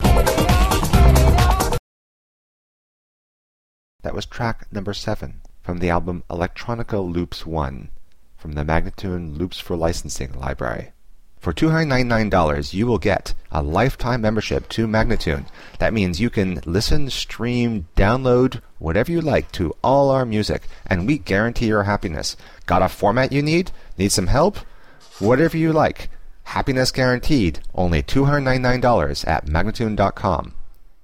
Instrumental samples in many genres.
135-C-ambient:teknology-1030